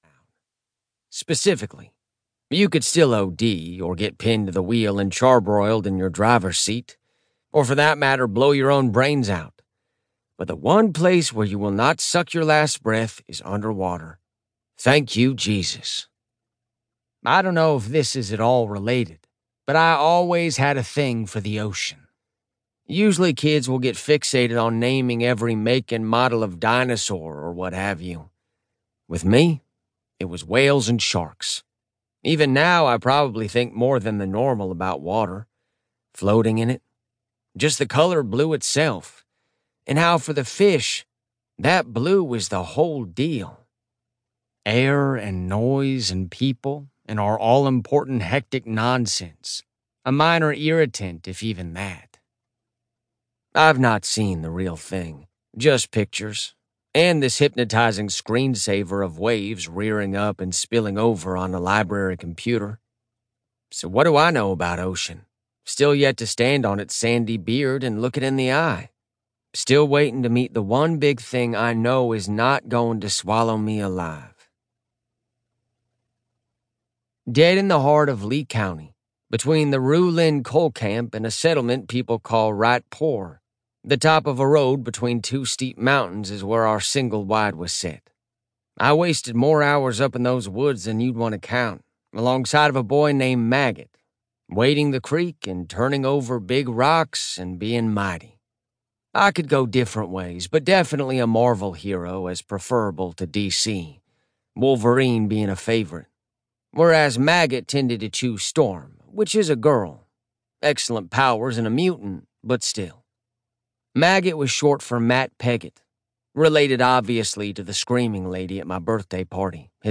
Preview-Demon-Copperhead-by-Barbara-Kingsolver.mp3